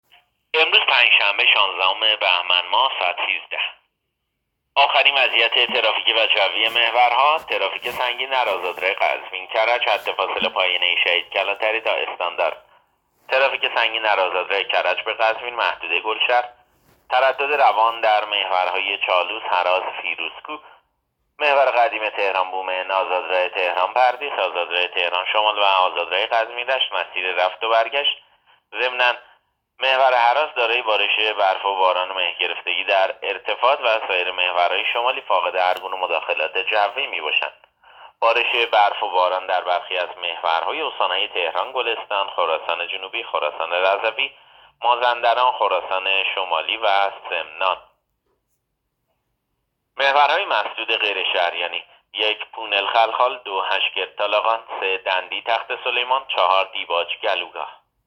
گزارش رادیو اینترنتی از آخرین وضعیت ترافیکی جاده‌ها ساعت ۱۳ شانزدهم بهمن؛